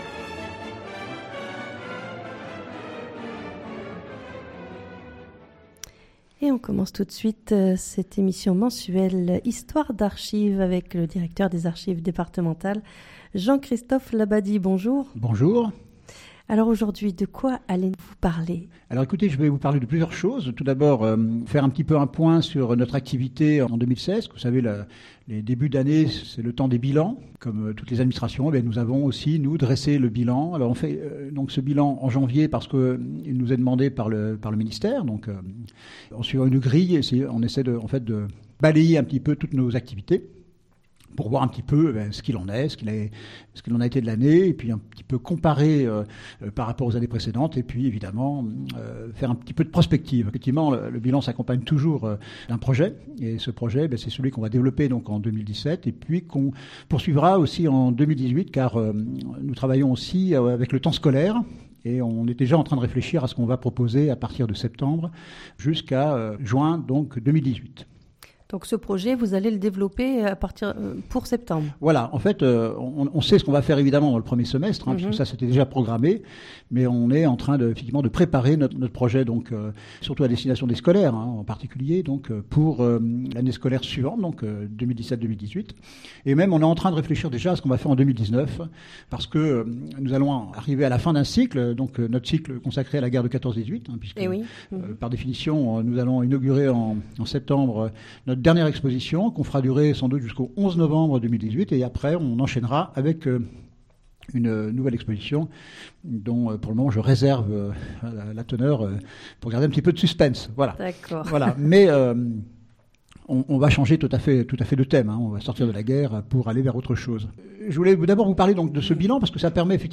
Cette émission sur fréquence mistral à Digne, a lieu tous les 3èmes jeudi du mois en direct de 9h10 à 10h